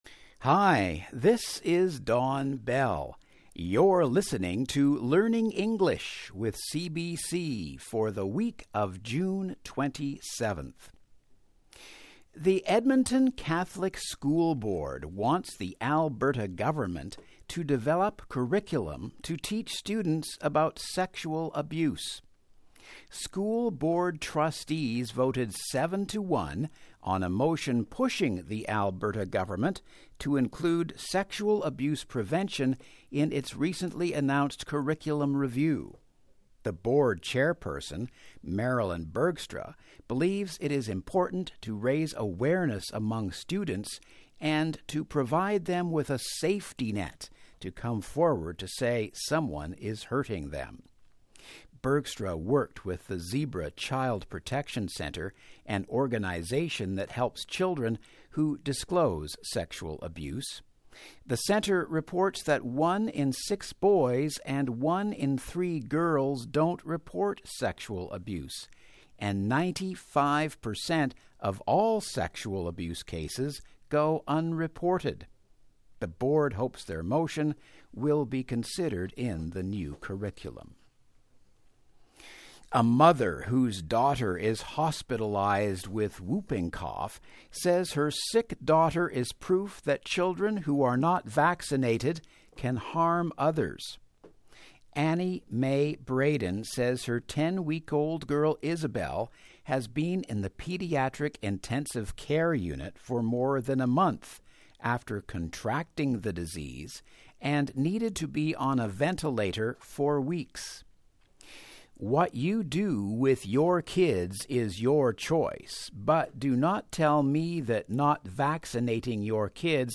Edmonton weekly newscast lesson plan for June 27, 2016